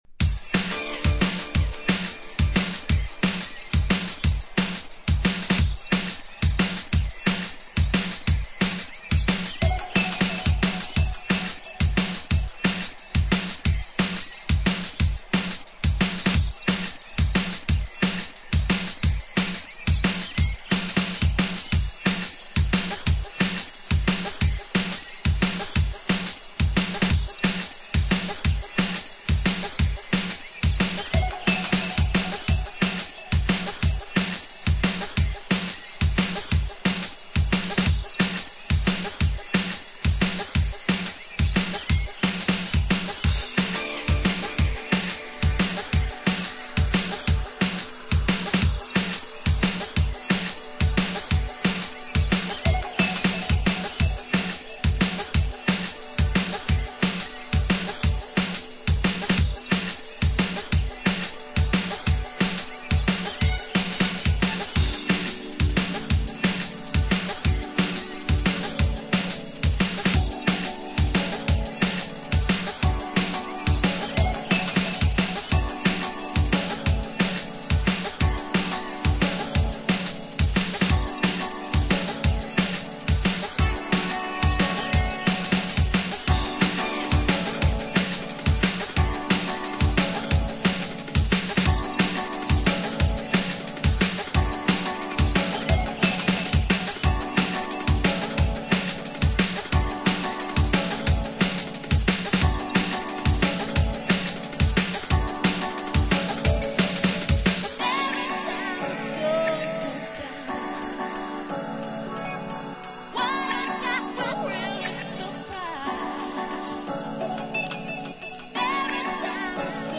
funky drumnbass.